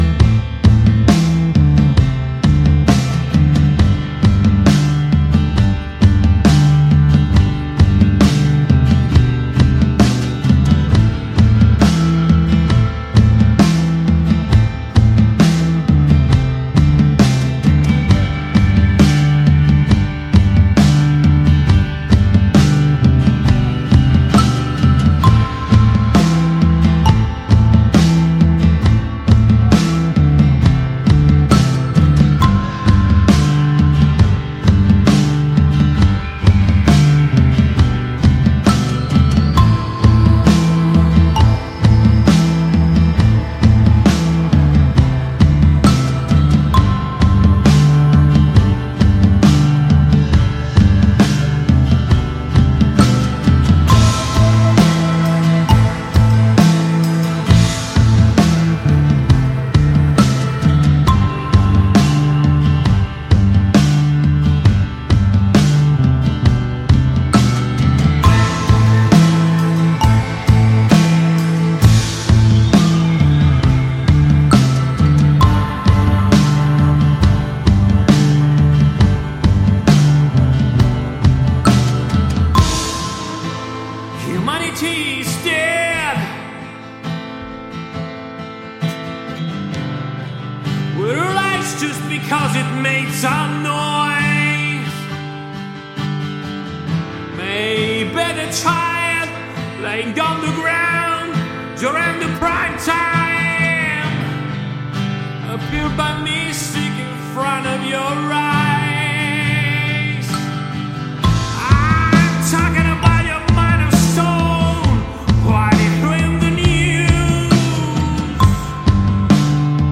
una chiacchierata arricchita dall’ascolto di tre canzoni.